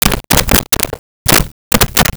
Switchboard Telephone Dialed 03
Switchboard Telephone Dialed 03.wav